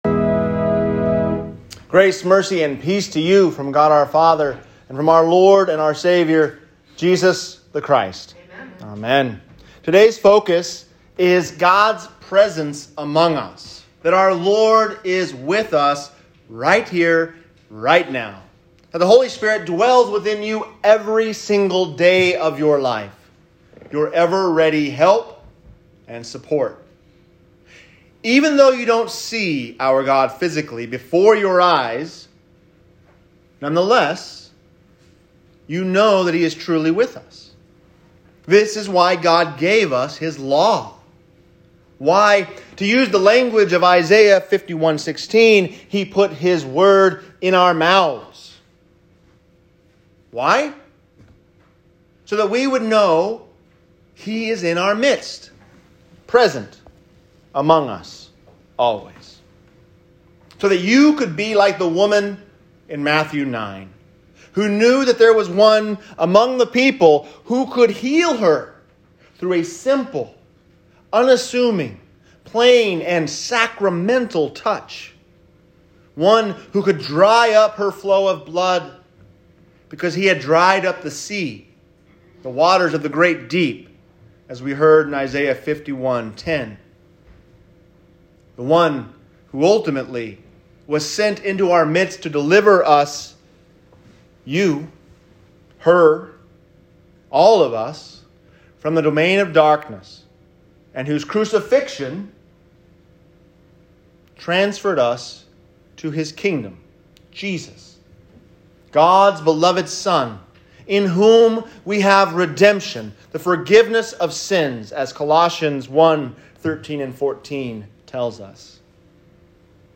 God’s Presence Among Us | Sermon